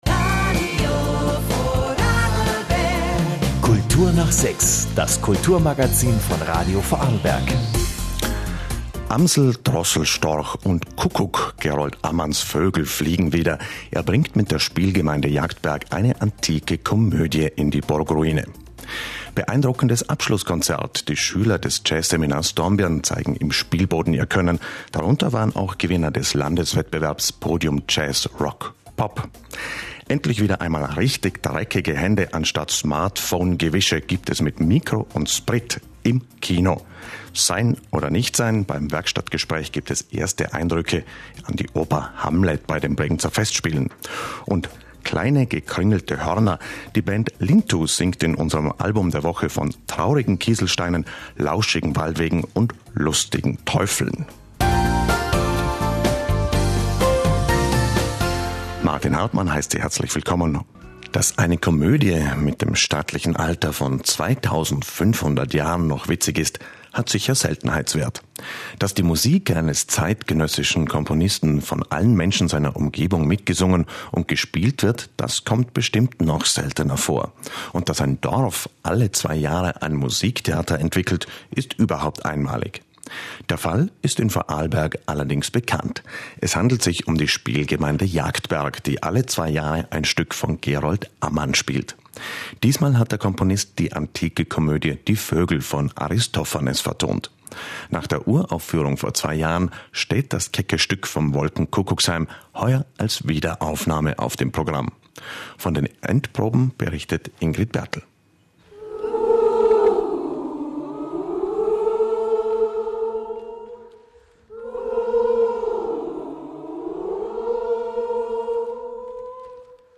Endprobenbericht auf ORF Radio Vorarlberg, „Kultur nach 6“ vom 30. Juni 2016 [.mp3]